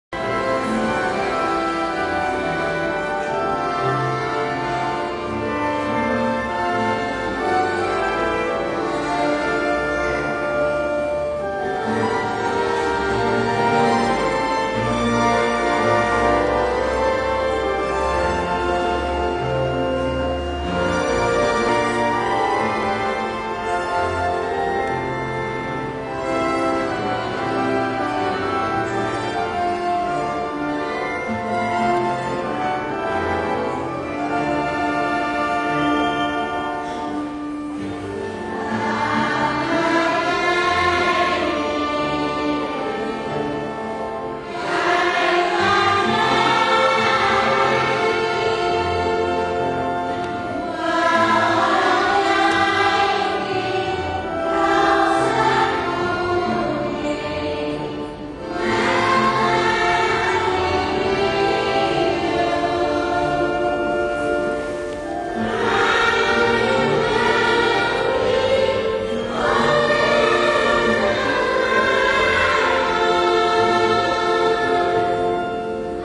Als we verder wandelen lopen we de kerk Santa Clara binnen. Een opvallend interieur dat voornamelijk uit honderden kleine spiegels bestaat; verder zit er een groep mensen te spelen en te zingen. Het is heel apart om te horen:
kerk-cusco.mp3